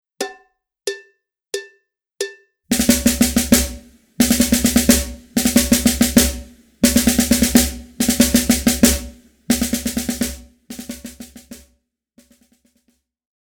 Besetzung: Schlagzeug
05 - 7-Stroke-Roll
05_-_7-Stroke-Roll.mp3